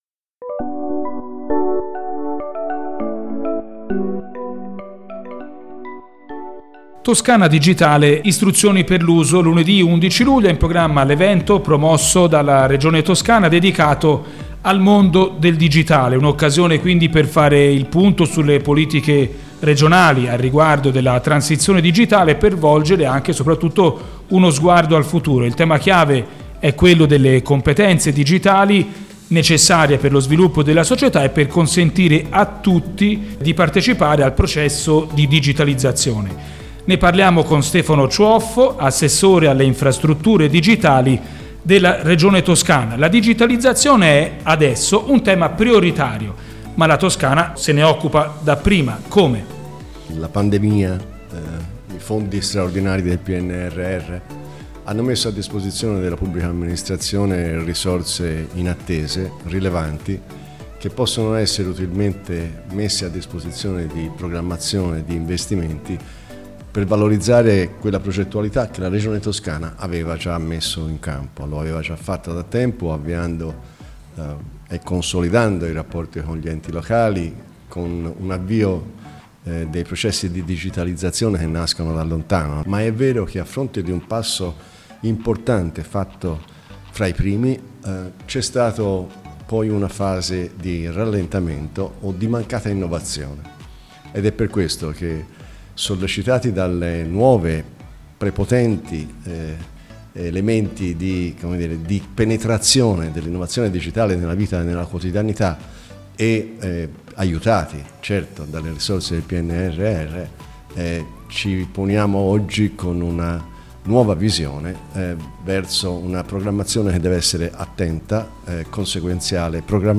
Lunedì 11 luglio al Teatro della Compagnia di Firenze
Ascolta l'intervento dell'Assessore alle Infrastrutture digitali, Stefano Ciuoffo: